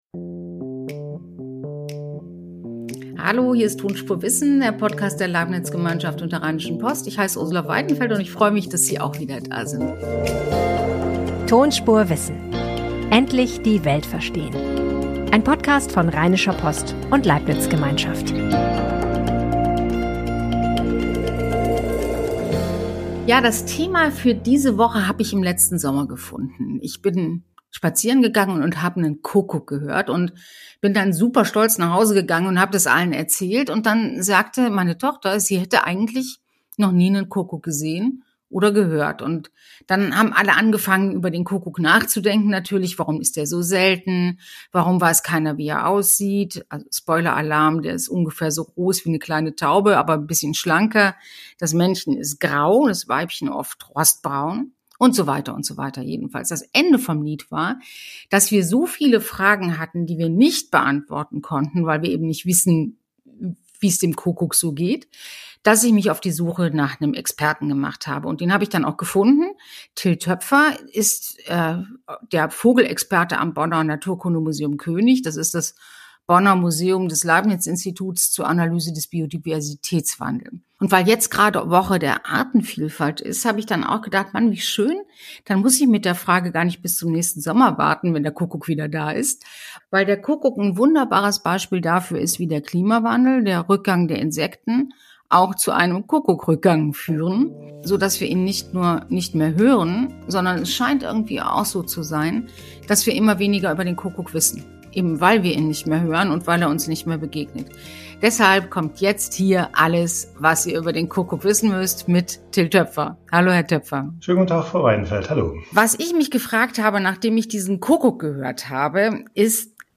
Alle haben schon einmal etwas vom Kuckuck gehört. Ein Vogelforscher erklärt, warum Brutparasitismus keine Bosheit, sondern eine raffinierte Überlebensstrategie ist.